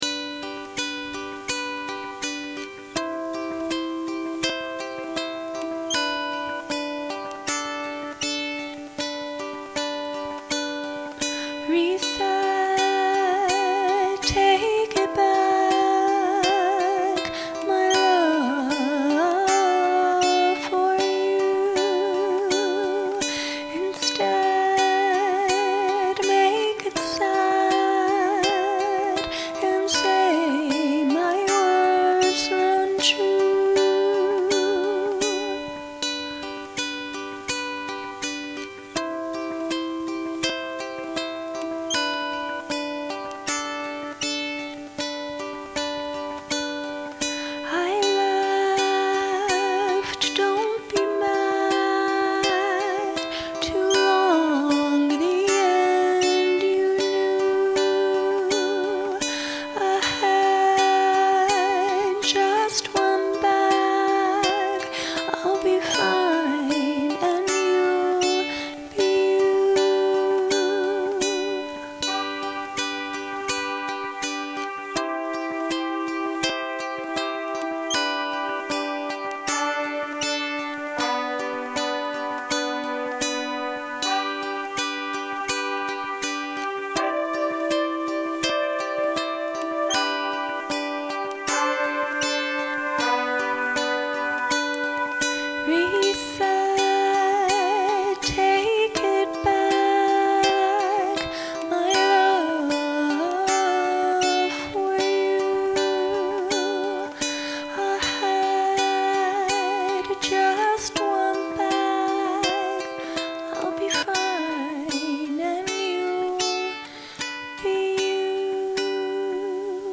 I retreated to my cabin often to write or record.
This song might sound a little sad or regretful, but I think it’s actually a very happy song.
“Reset” also includes my fanciest instrumentation EVER (eat you’re heart out, people with coordinated fingers) and some well meaning horns that need to be corrected one day.